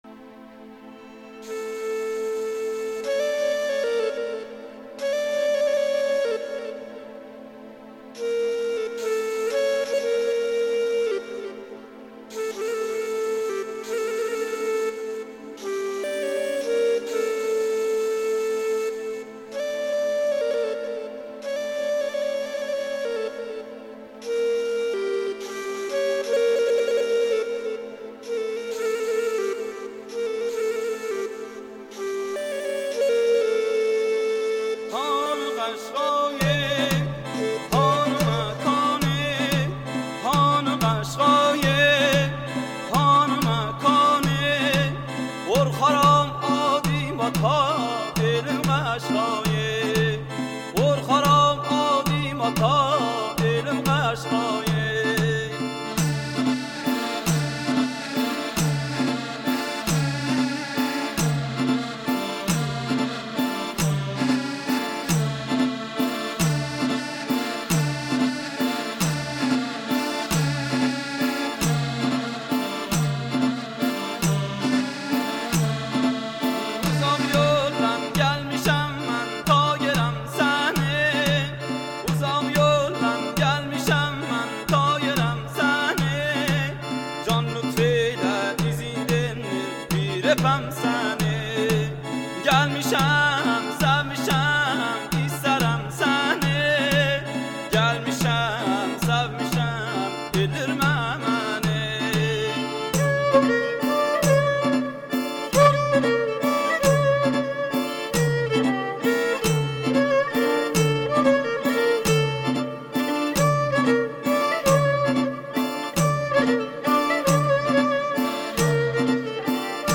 موسیقی ترکی قشایی